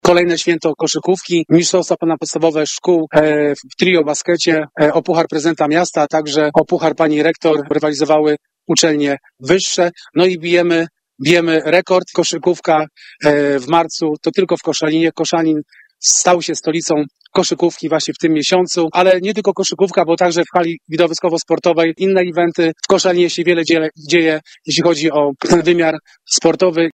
Mówi Sebastian Tałaj – wiceprezydent Koszalina: